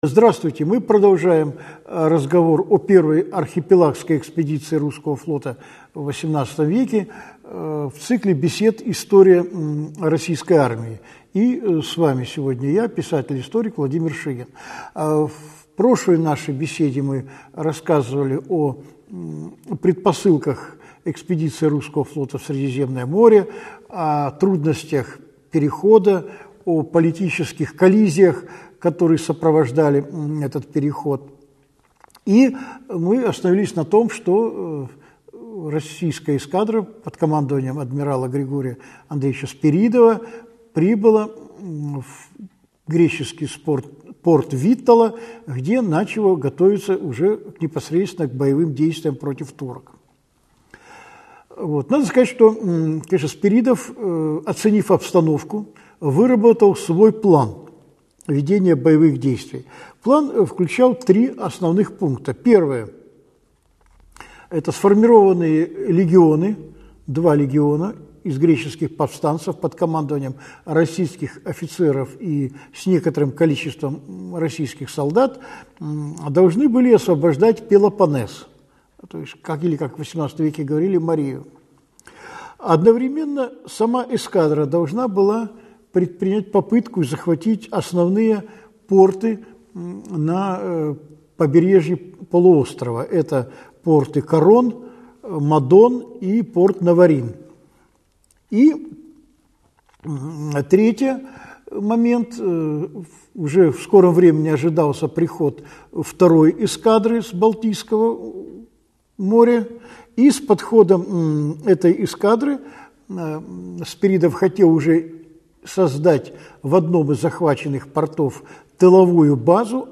Аудиокнига Чесменская победа. Часть 2 | Библиотека аудиокниг